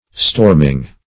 Storming \Storm"ing\,